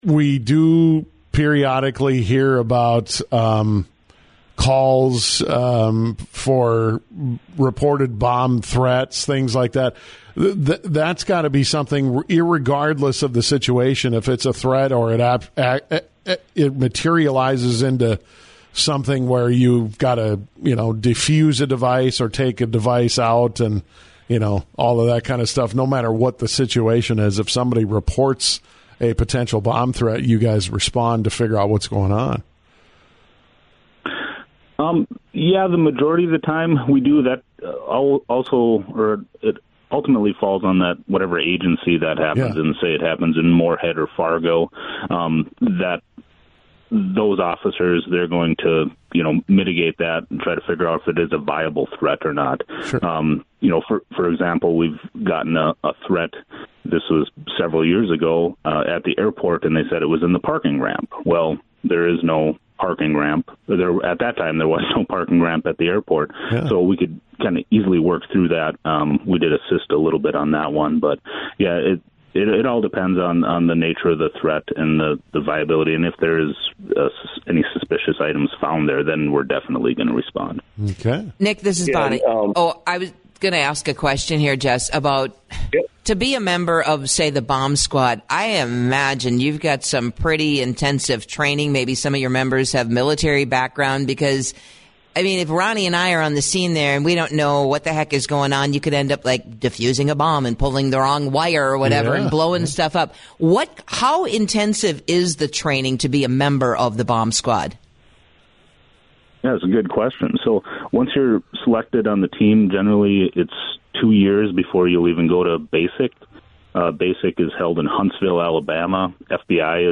Cass County Sheriff Jesse Jahner